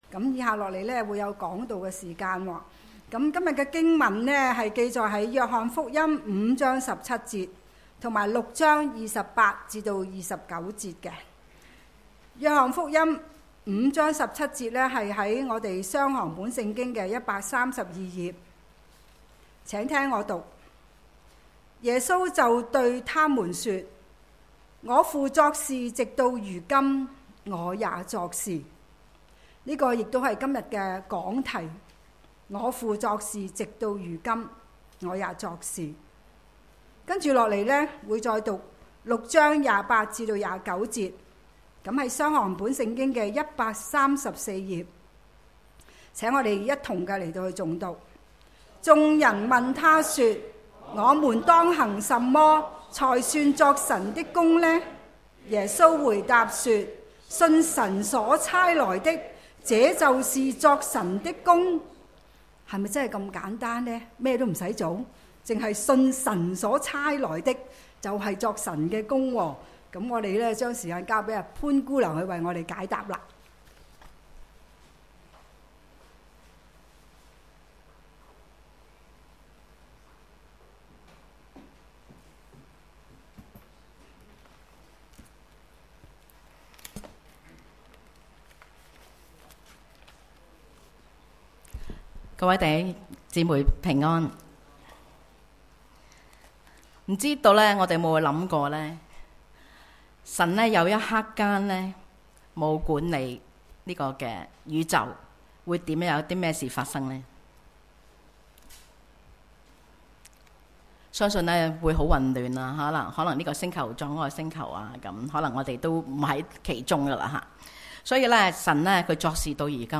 主日崇拜講道 – 我父作事直到如今，我也作事